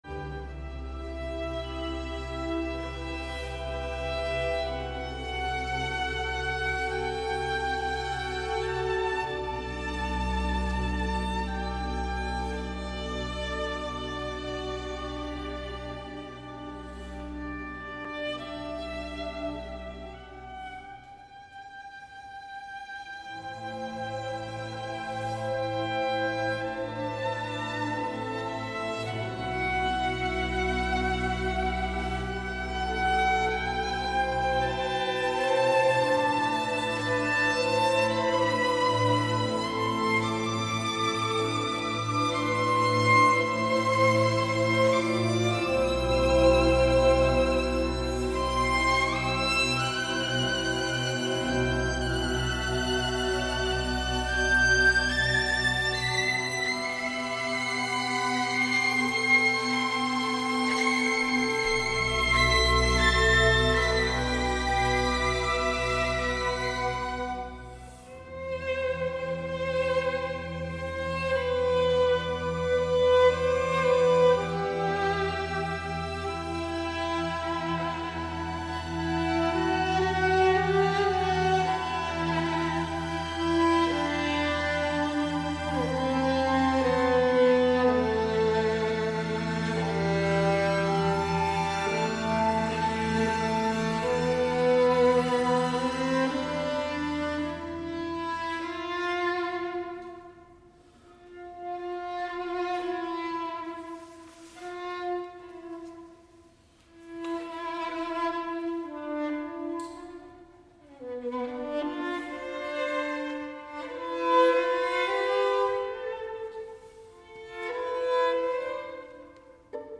Incontro con il violinista, musicista da camera e docente